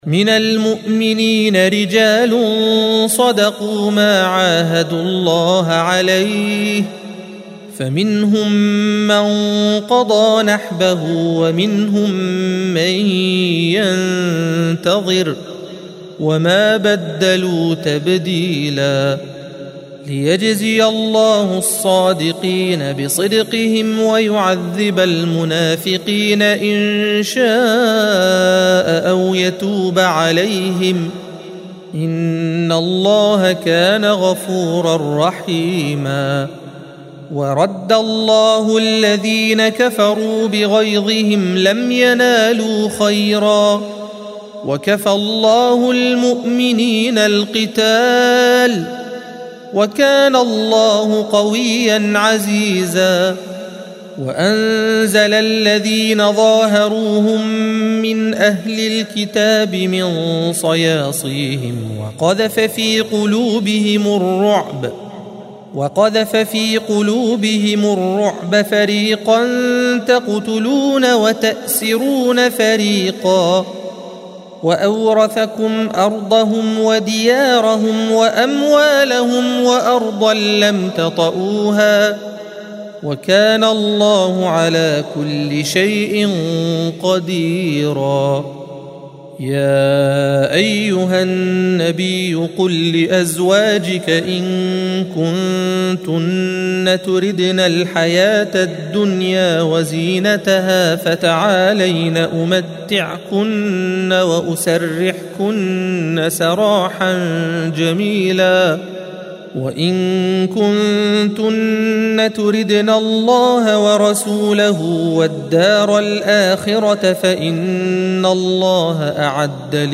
الصفحة 421 - القارئ